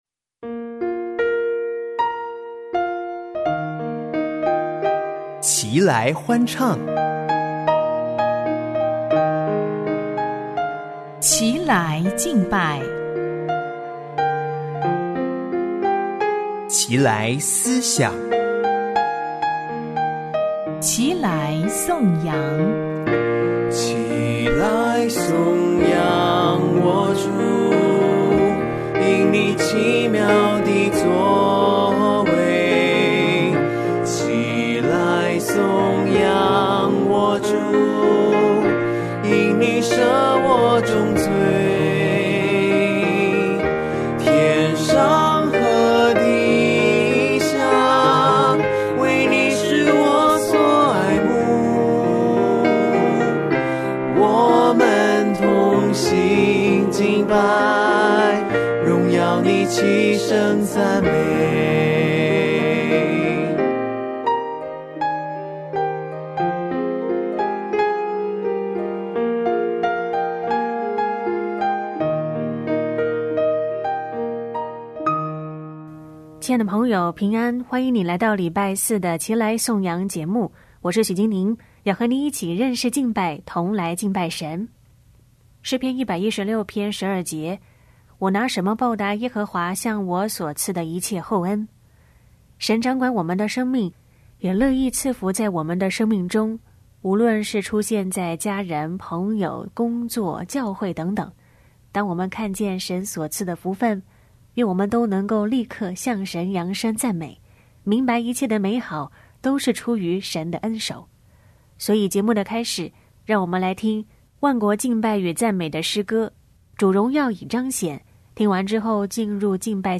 带我们敬拜赞美神！